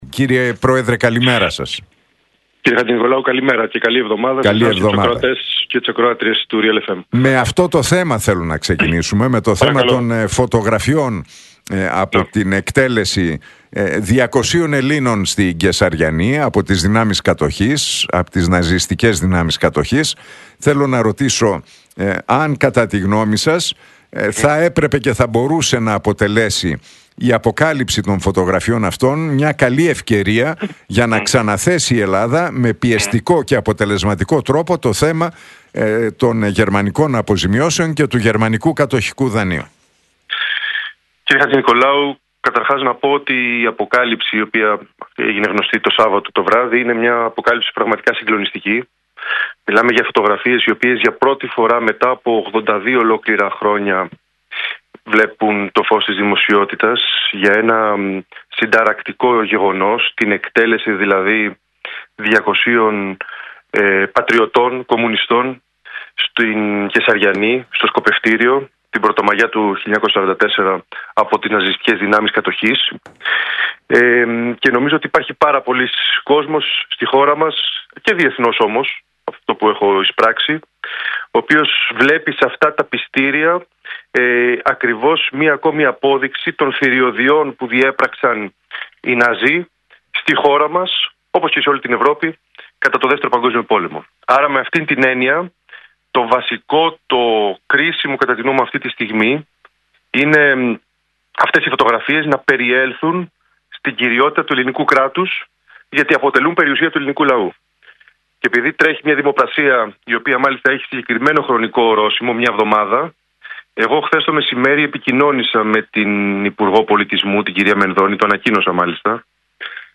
Συνέντευξη στον Realfm 97,8 και την εκπομπή του Νίκου Χατζηνικολάου παραχώρησε ο πρόεδρος της Νέας Αριστεράς, Αλέξης Χαρίτσης.